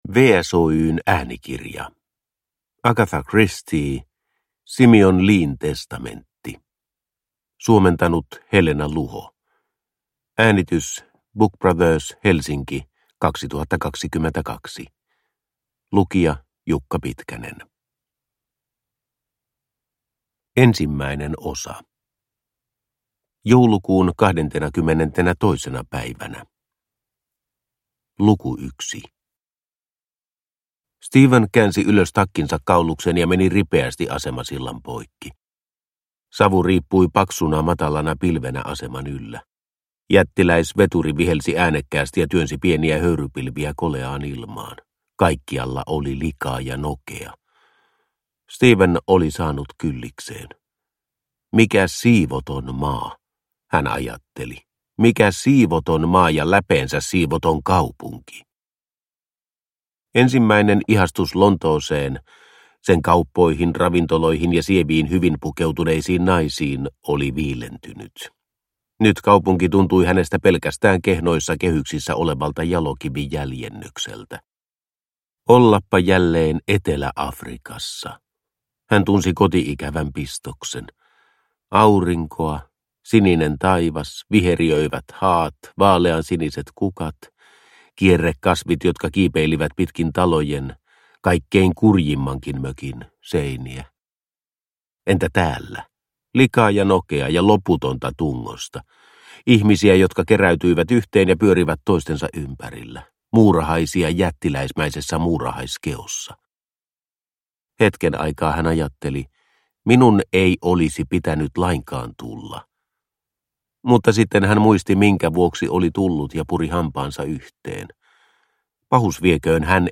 Simeon Leen testamentti – Ljudbok – Laddas ner